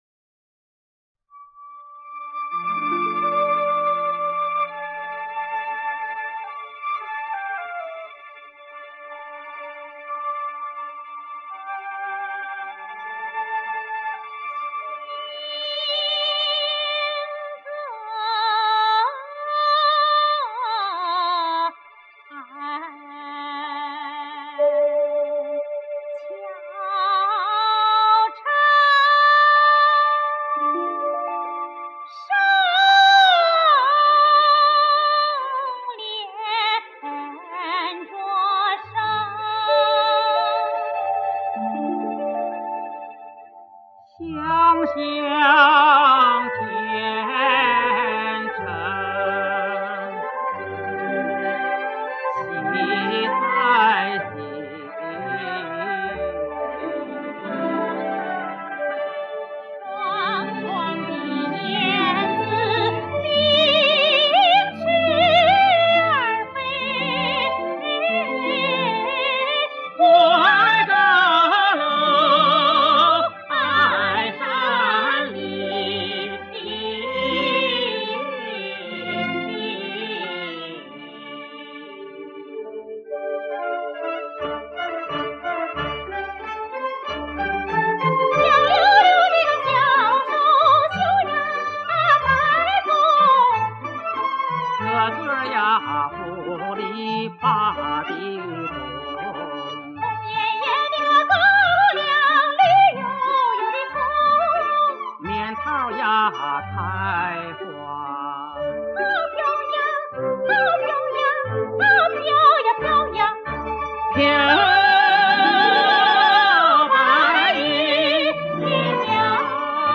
老电影原声